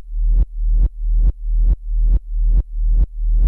REVERSE BA-R.wav